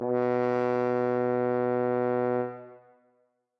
这些样本是使用各种硬件和软件合成器以及外部第三方效果创建的。